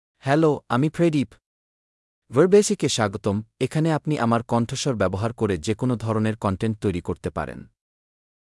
Pradeep — Male Bangla (Bangladesh) AI Voice | TTS, Voice Cloning & Video | Verbatik AI
Pradeep is a male AI voice for Bangla (Bangladesh).
Voice sample
Male
Pradeep delivers clear pronunciation with authentic Bangladesh Bangla intonation, making your content sound professionally produced.